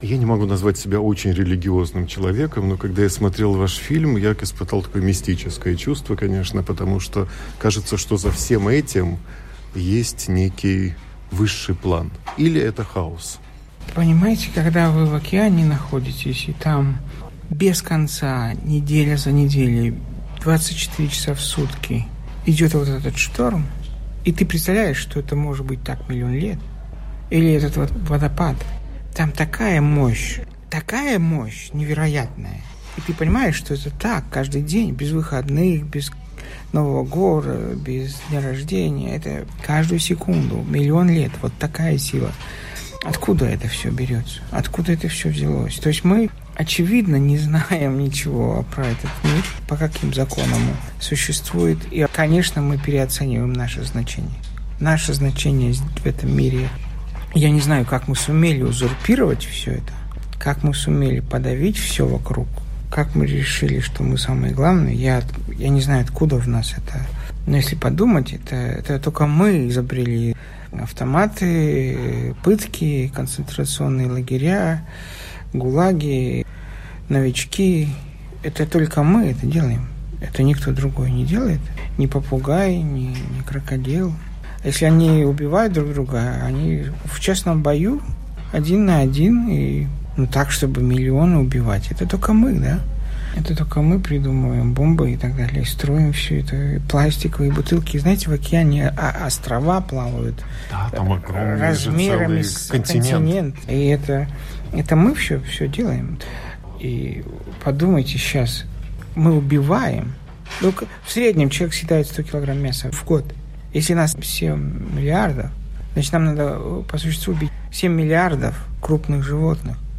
Разговор с создателем "Акварелы"